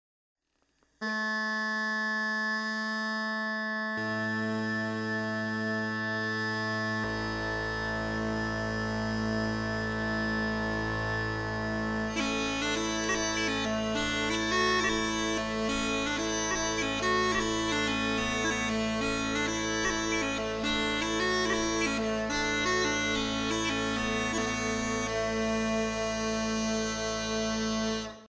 Contrabass drone for Scottish smallpipes
In which we somehow try to fit six feet of contrabass A1 drone into a Scottish smallpipe.